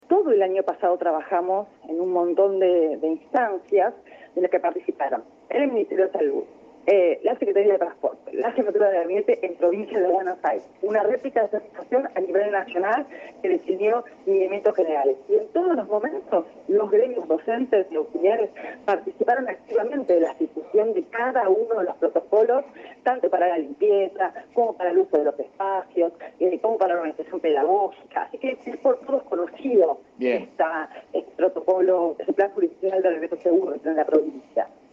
Declaraciones de Agustina Vila en Radio AM Provincia